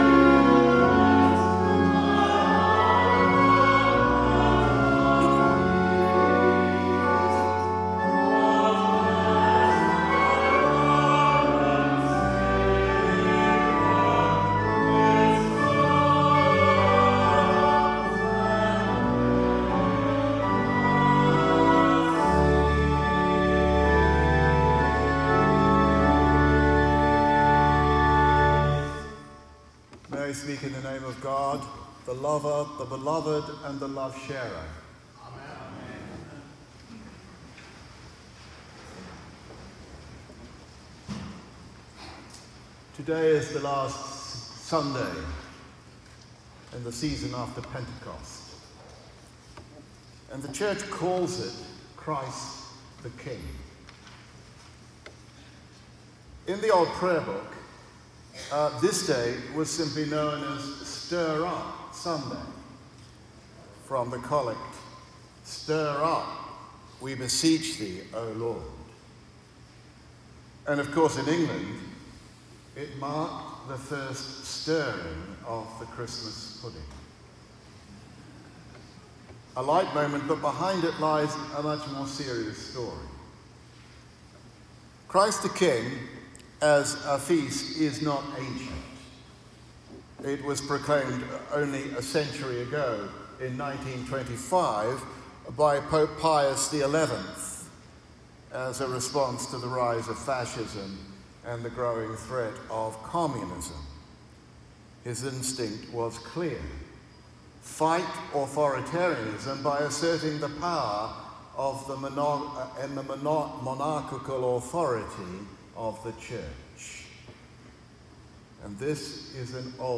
Audio: Note the recording is a version of the text below streamlined for oral delivery
Sermon